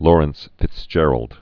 (lôrənts-fĭts-jĕrld)